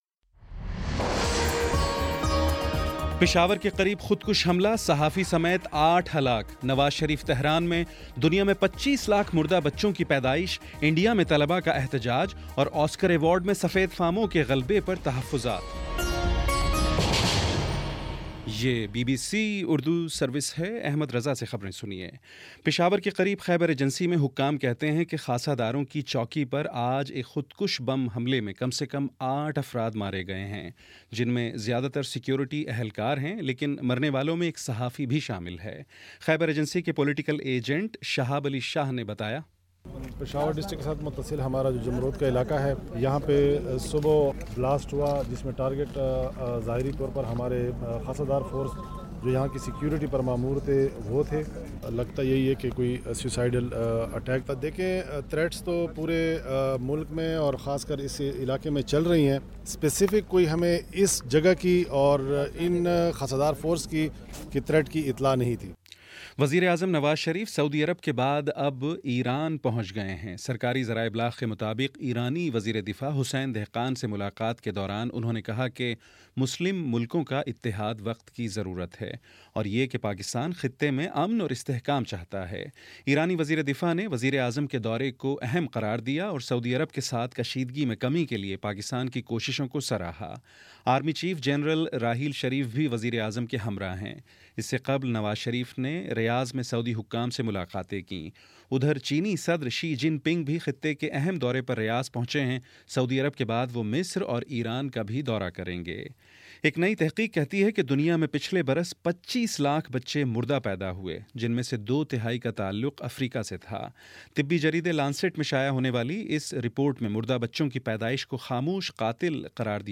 جنوری 19 : شام پانچ بجے کا نیوز بُلیٹن